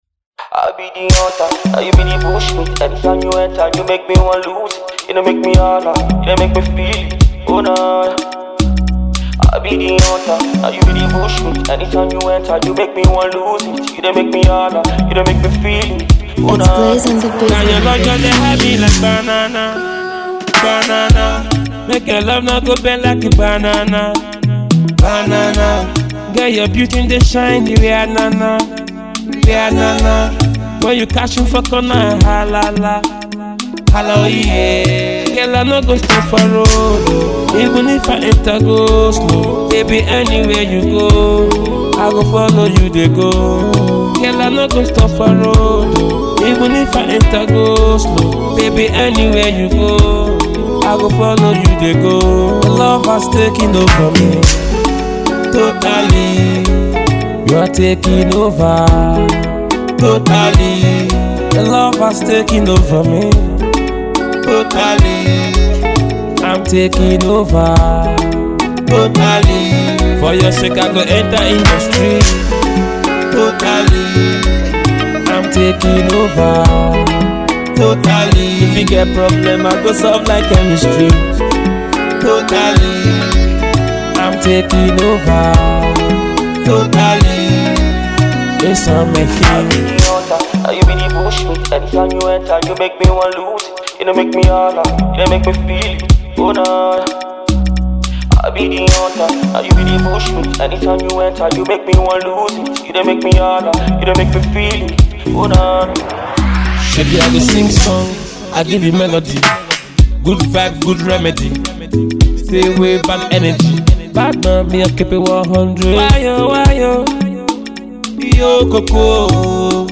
Afro-pop
The south south singer
sweet love song